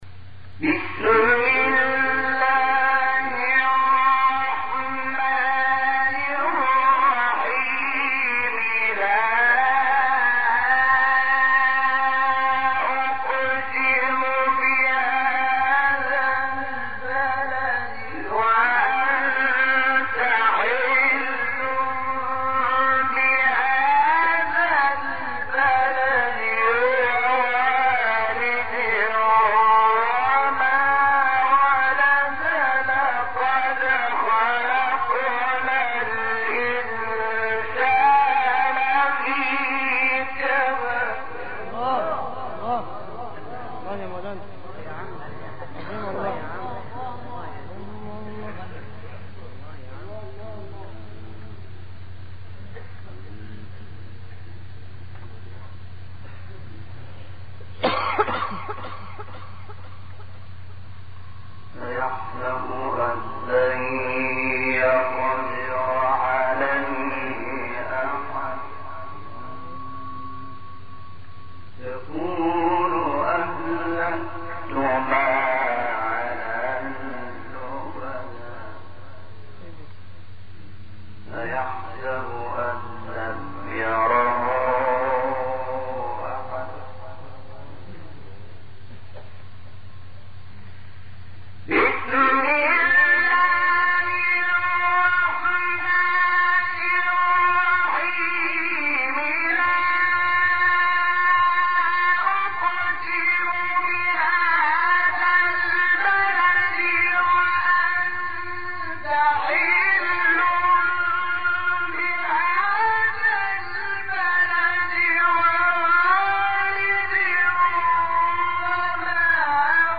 تلاوت صوتی سوره بلد استاد شحات | نغمات قرآن
سوره: بلد آیه: از ابتدا تا انتهای سوره استاد : شحات مقام : مرکب خوانی (ترکیب صبا و عجم * نهاوند * رست) قبلی بعدی